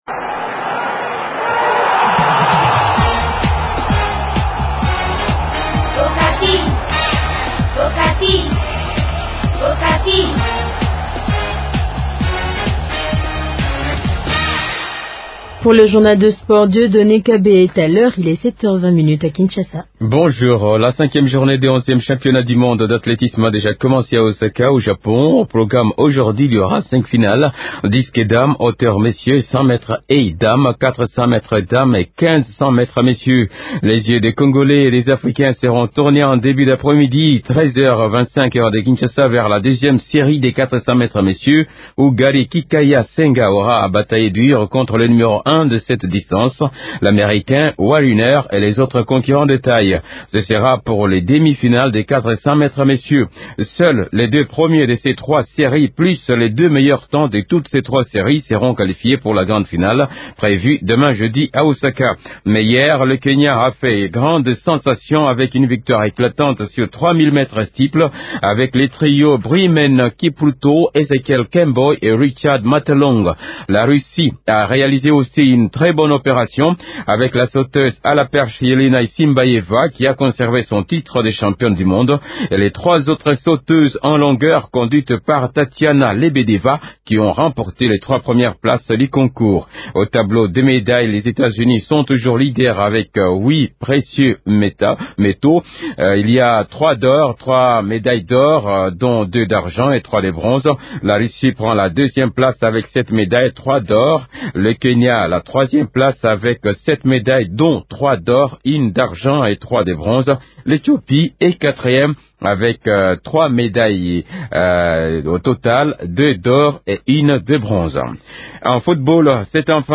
interviewé